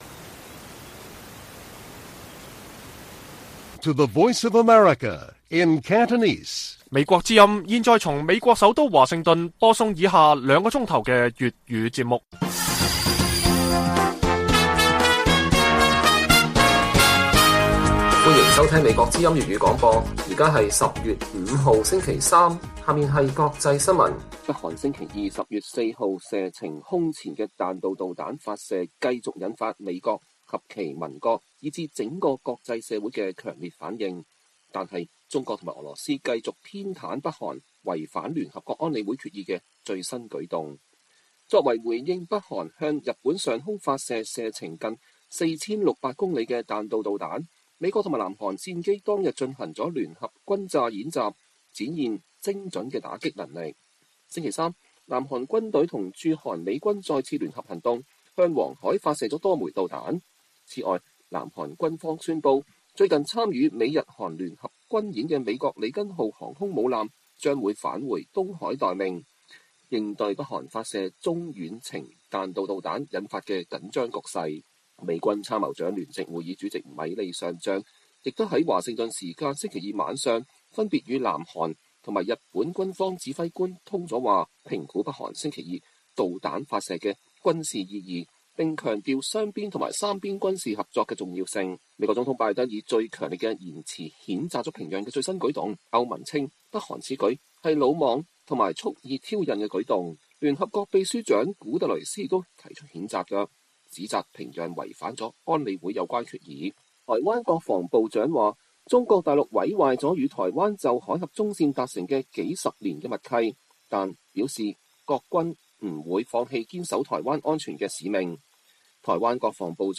粵語新聞 晚上9-10點: 美國會發布報告詳載香港公民社會面臨瓦解